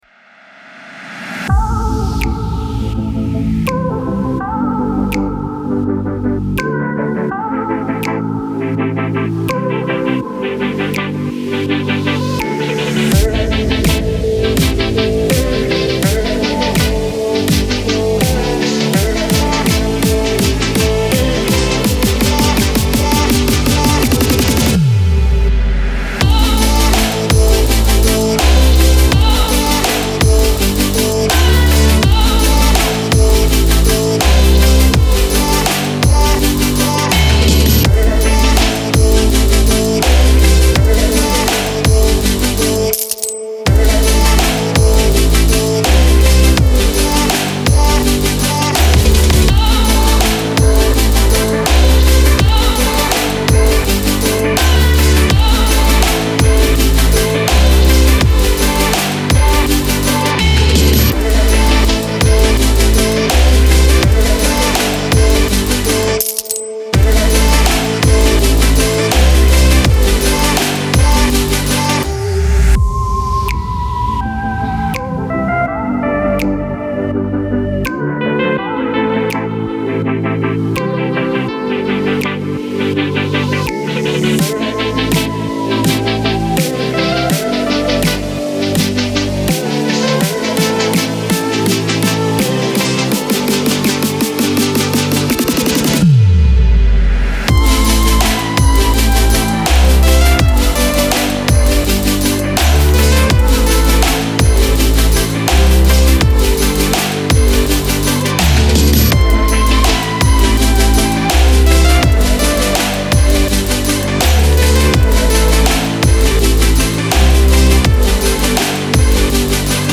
Future-Bass.mp3